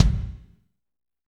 Index of /90_sSampleCDs/Northstar - Drumscapes Roland/KIK_Kicks/KIK_F_R Kicks x